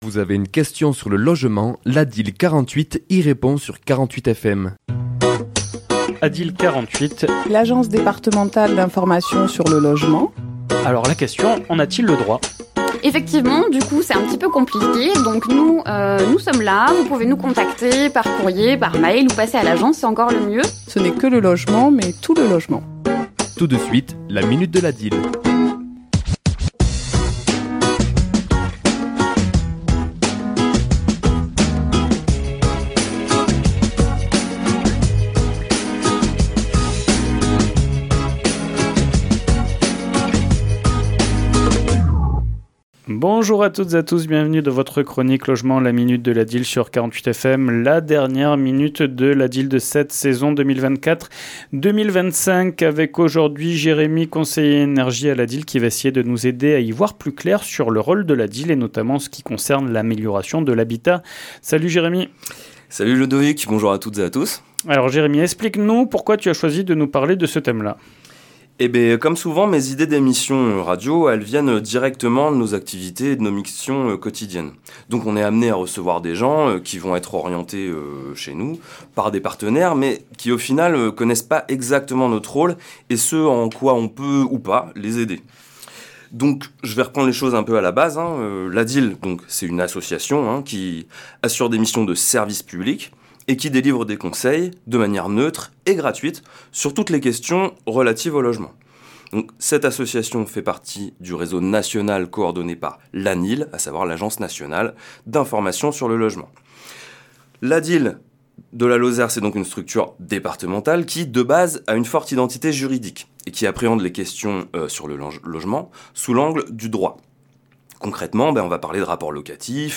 Chronique diffusée le mardi 24 juin à 11h et 17h10